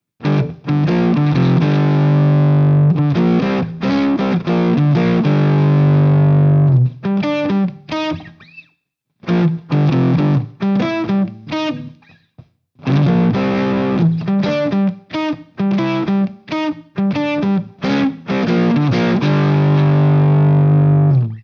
SATURATION AND MULTI-AMP BLENDING
For example, you could start with the AC Box amplifier, then place an instance of Citrus after it, and top it all off with a Tweedman for some vintage midrange boost.